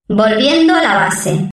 Musiky Bass Free Samples: Voz